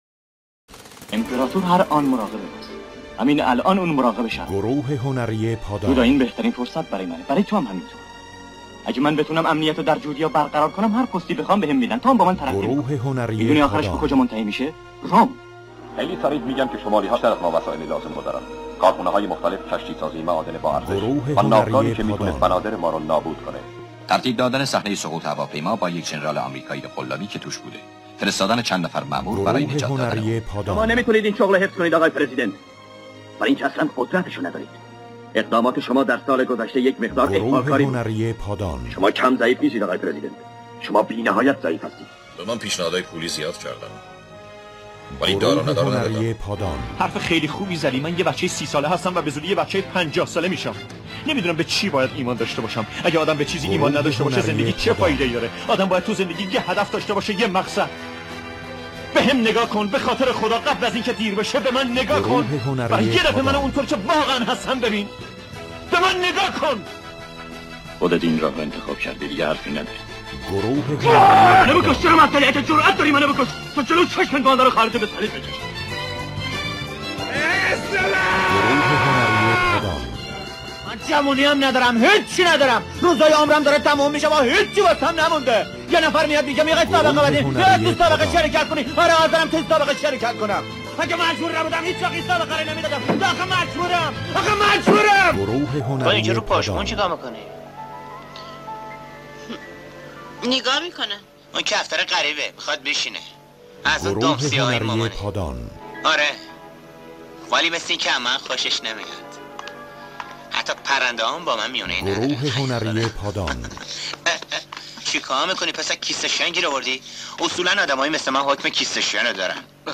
چنگیز جلیلوند در میان دوبلورهای ایران به مرد حنجره طلایی معروف بود زیرا تن صدای او قابلیت بسیار خوبی برای تیپ سازی داشت.
نمونه کار دوبله چنگیز جلیلوند
changiz-jalalvand-dub.mp3